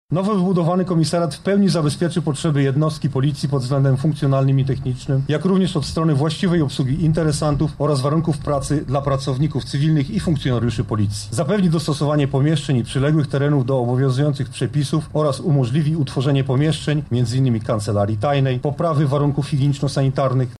O tym, jakie jeszcze warunki ma spełniać budynek, mówi Komendant Miejski Policji w Lublinie inspektor Dariusz Dudzik: